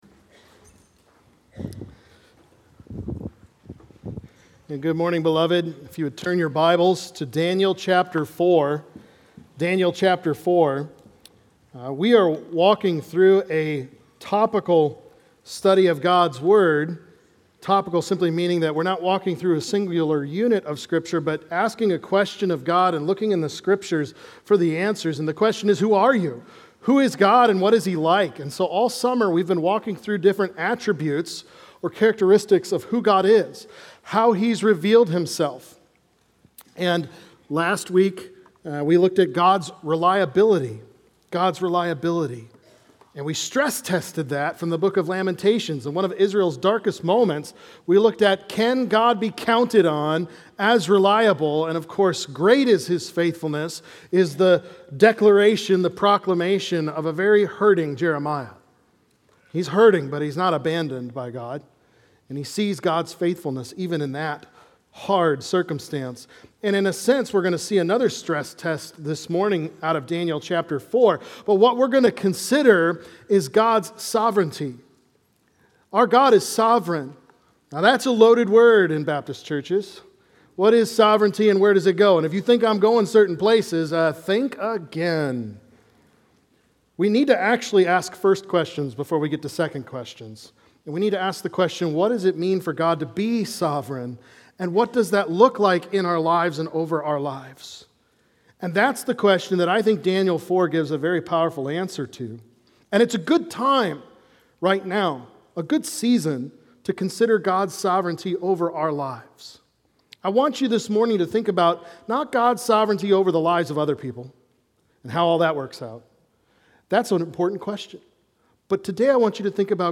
God Reigns | Baptist Church in Jamestown, Ohio, dedicated to a spirit of unity, prayer, and spiritual growth